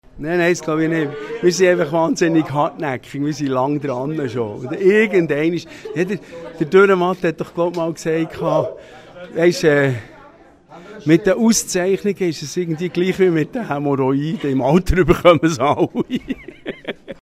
Patent Ochsner bekam sie als „Bester Liveact“. Dass die Berner etwas besser machen als andere, glaubt der Frontmann Büne Huber aber nicht, wie er in Interview gegenüber Radio BeO erklärt. Vielmehr habe es mit Geduld zu tun, wie er schmunzelnd sagt.